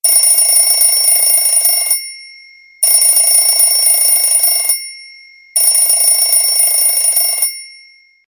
old-alarm-sound_24820.mp3